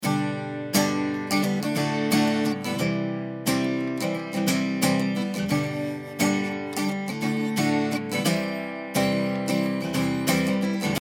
すると0.1刻みでスロープが設定できるではありませんか！
確かに聞いた感じもその前後の値では得られなかったちょうど良さを感じます。